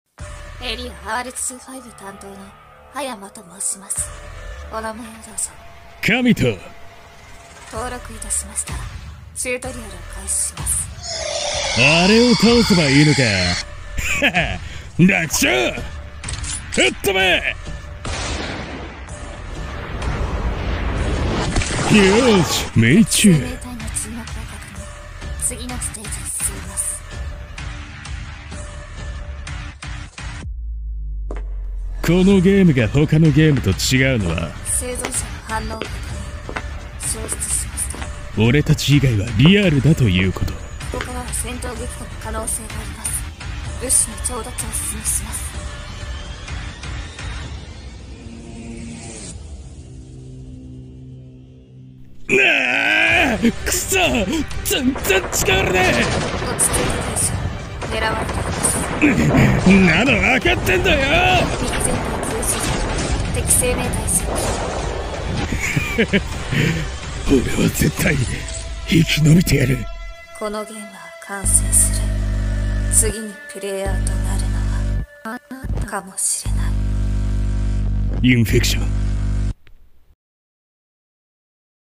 【2人声劇】インフェクション